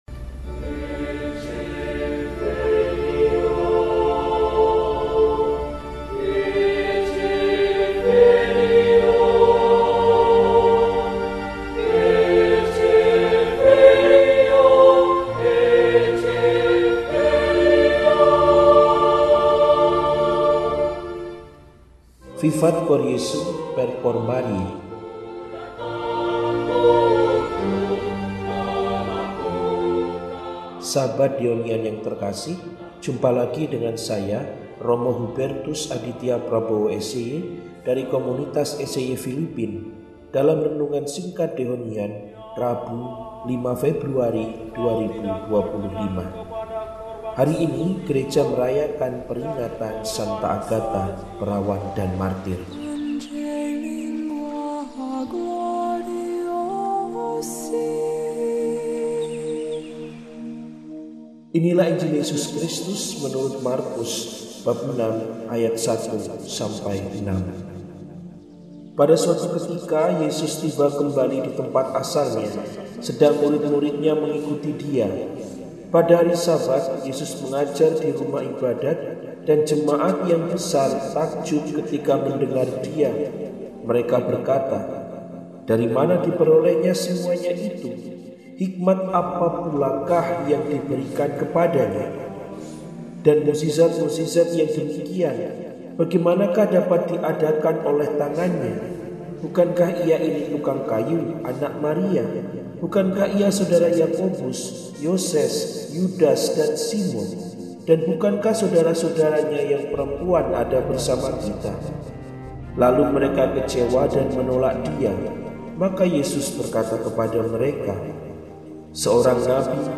Agata, Perawan dan Martir – RESI (Renungan Singkat) DEHONIAN